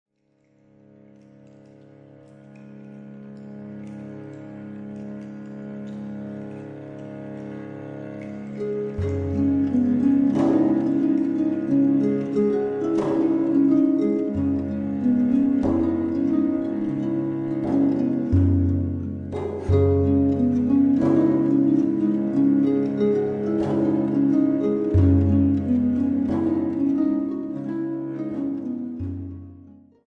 Harpe, Violoncelle, Flûtes à bec et Viole de Gambe